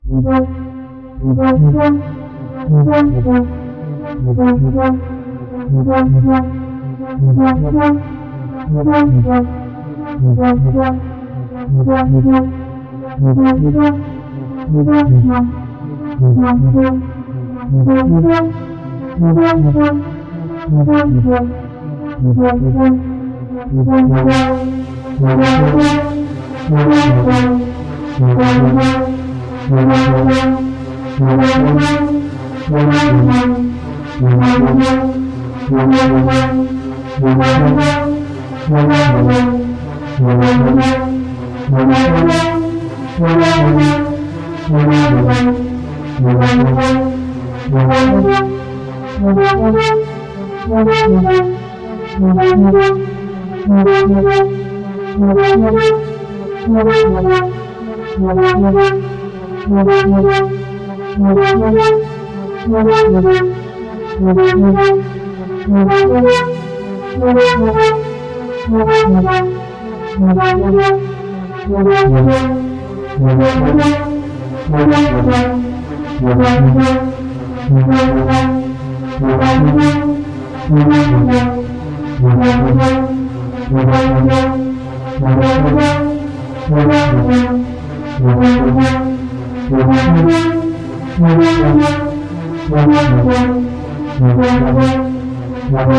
I'm still sketching out the Donsol soundtrack, I really like these ultra slowed chatty synth lines, unusable, but nice.